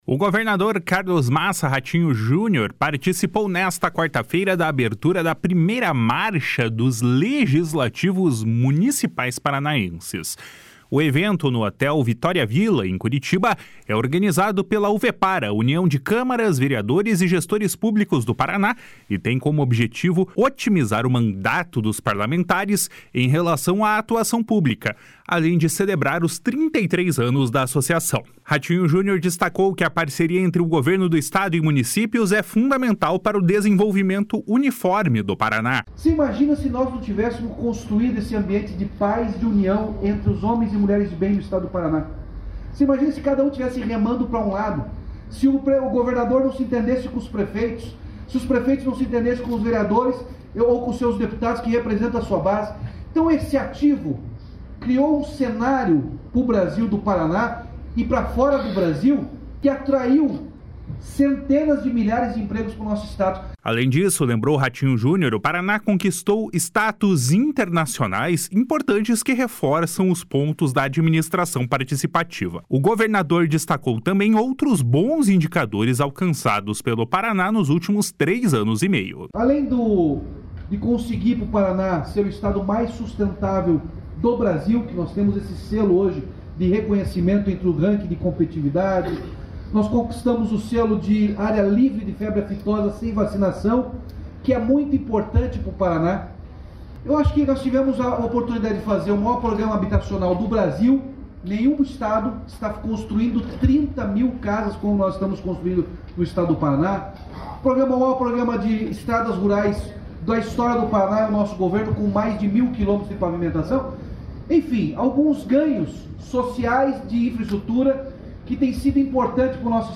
O governador Carlos Massa Ratinho Junior participou nesta quarta-feira da abertura da 1ª Marcha dos Legislativos Municipais Paranaenses.
Ratinho Junior destacou que a parceria entre Governo do Estado e municípios é fundamental para o desenvolvimento uniforme do Paraná. // SONORA RATINHO JUNIOR //